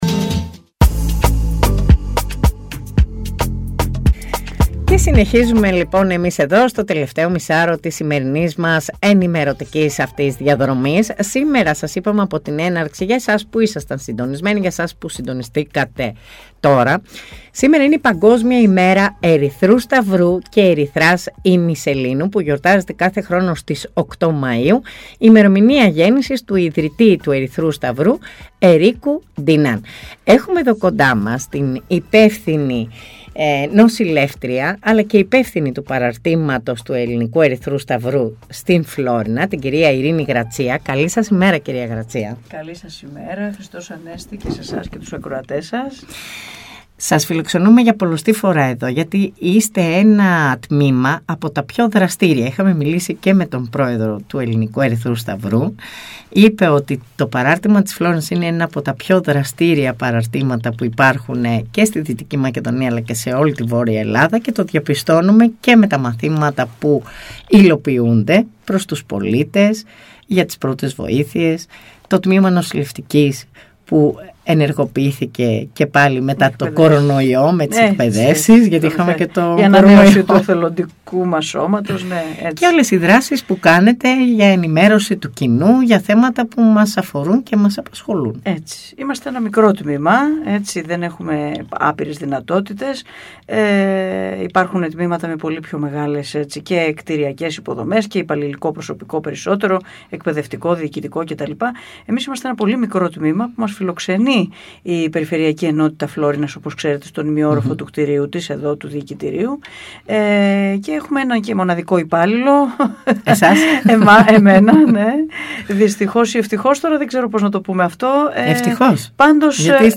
στο studio της ΕΡΤ Φλώρινας